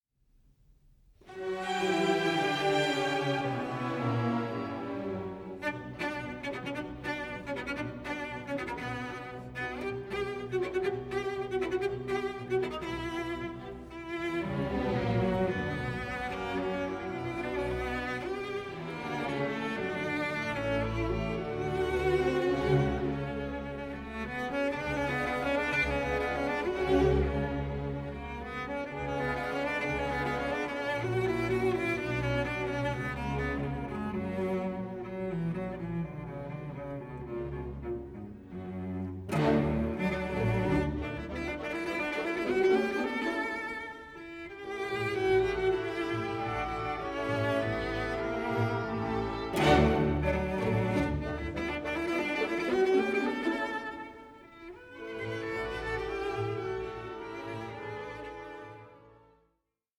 9) for Cello and Orchestra, Op. 43 4:17